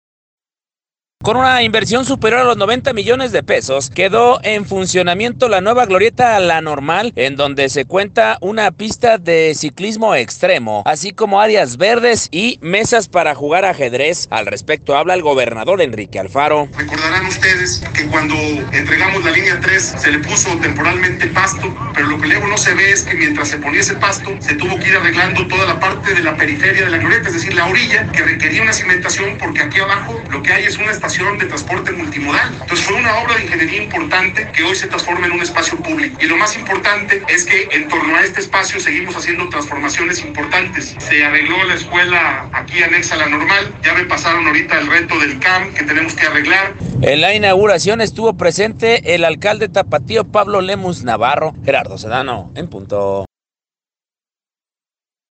Con una inversión superior a los 90 millones de pesos, quedó en funcionamiento la nueva Glorieta la Normal, en donde se encuentra una pista de ciclismo extremo, así como áreas verdes y mesas para jugar ajedrez. Al respecto habla el gobernador, Enrique Alfaro: